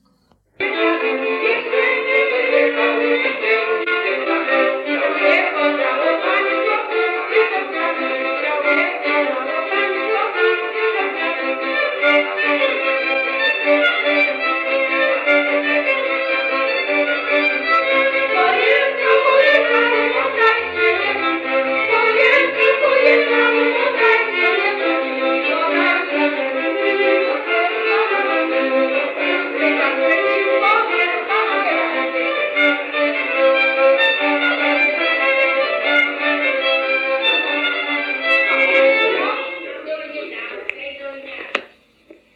Jeszcze nie przejechał – Żeńska Kapela Ludowa Zagłębianki
Nagrania archiwalne (I skład kapeli)